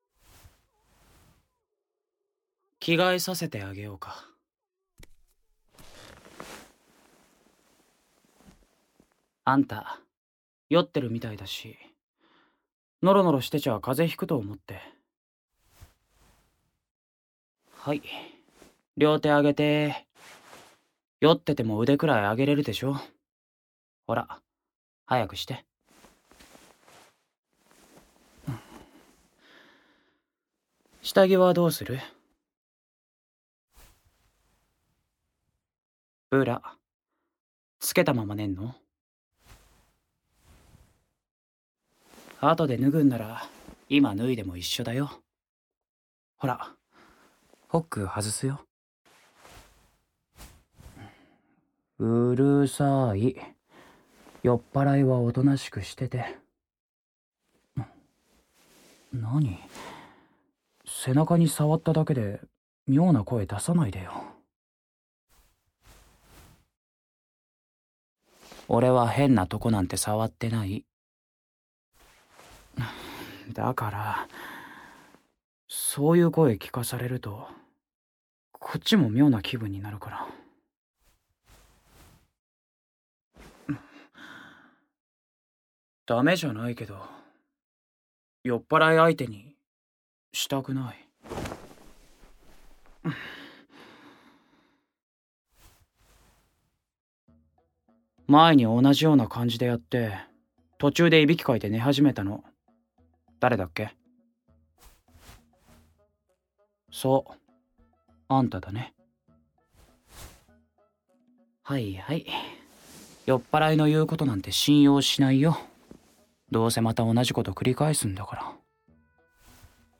●全編ダミーヘッドマイクにて収録